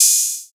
Closed Hats
HiHat (3).wav